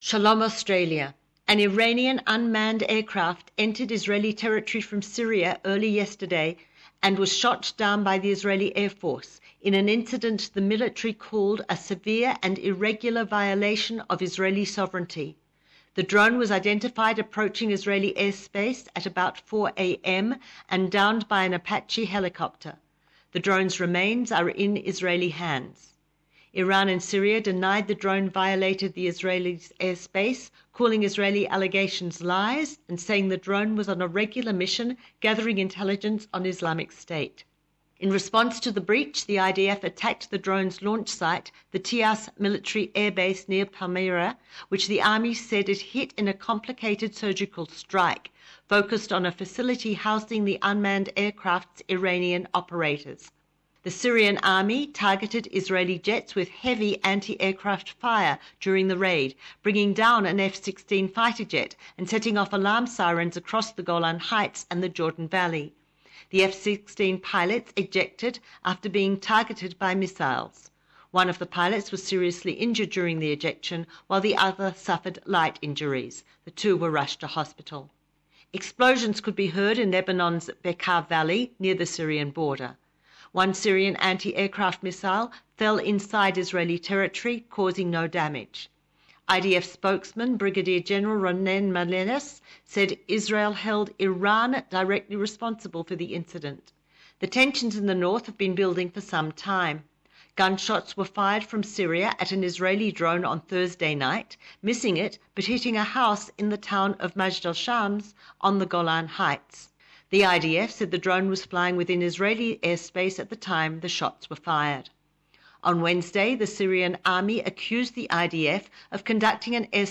reporting in English from Jerusalem.